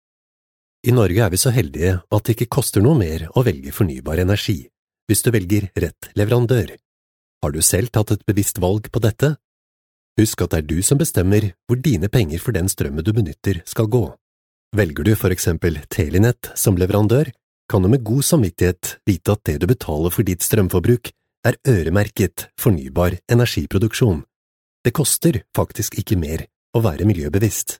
Native speaker Male 30-50 lat
Nagranie lektorskie